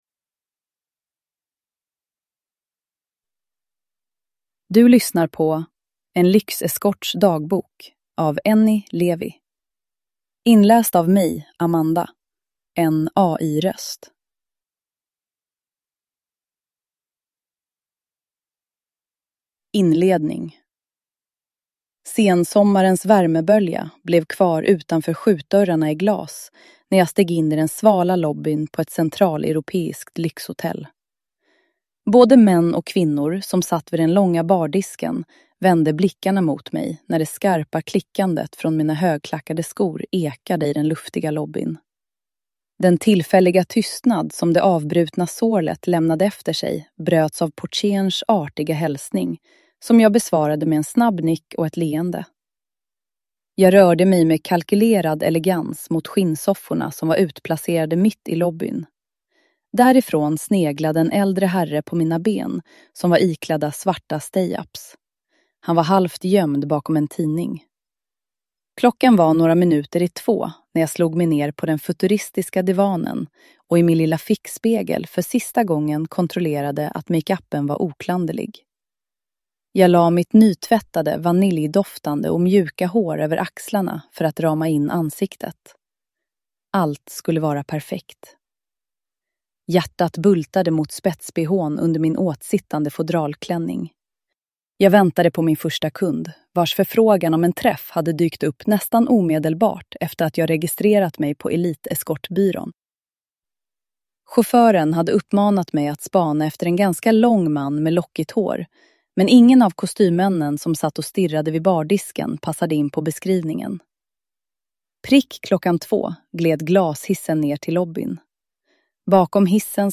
En lyxeskorts dagbok – Ljudbok
Uppläsare: AI (Artificial Intelligence)